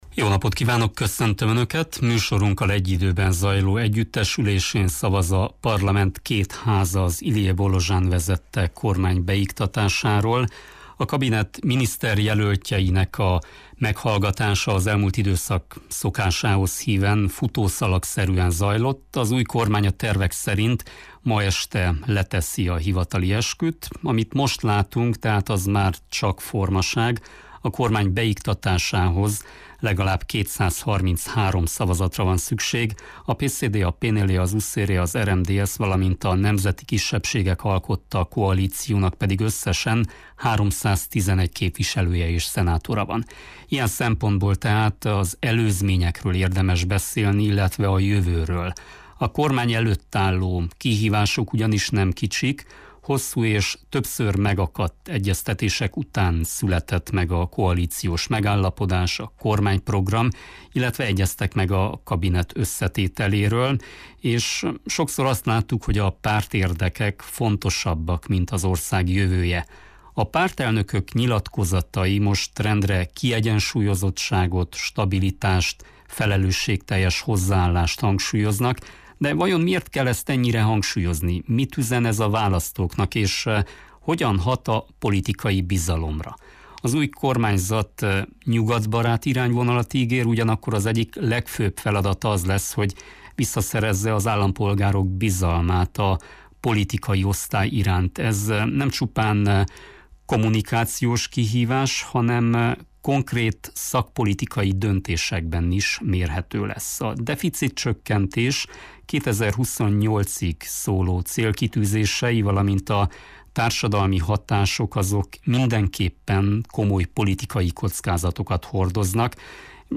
beszélgetésünket a rádióban élőben sugároztuk.